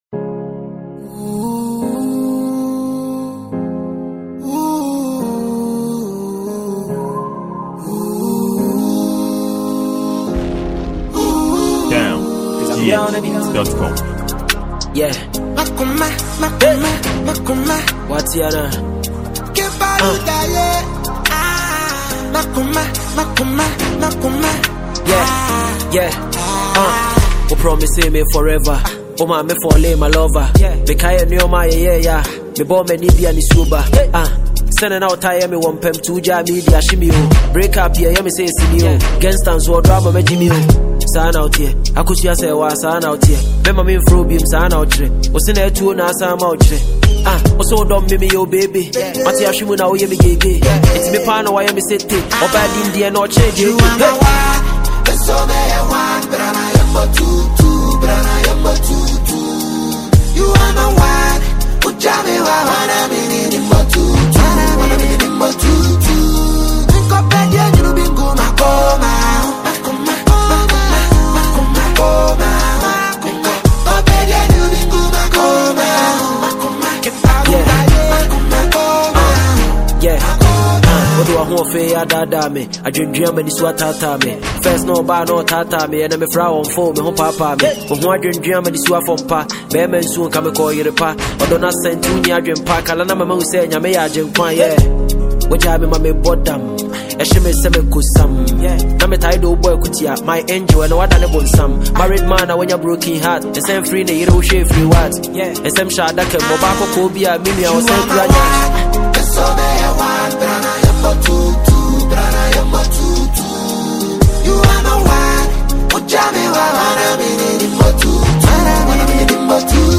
This is a pure Ghanaian afrobeats and highlife genre.